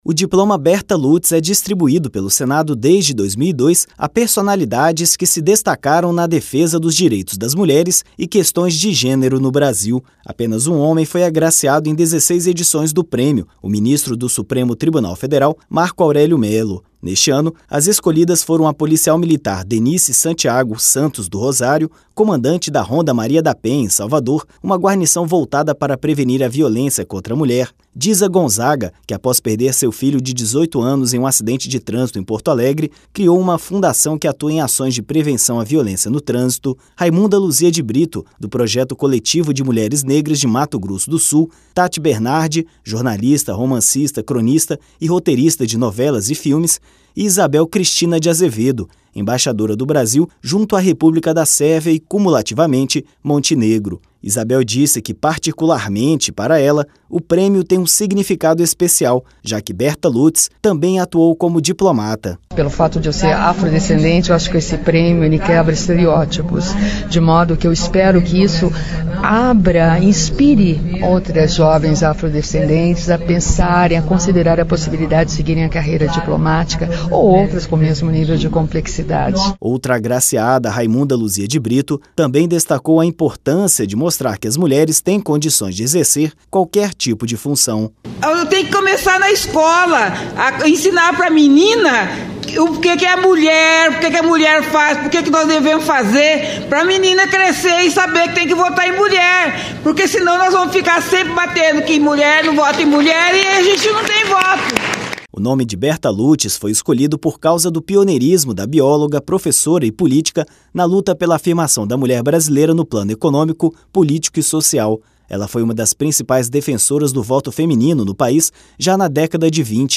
A premiação faz parte das comemorações pelo Dia Internacional da Mulher, como mostra a reportagem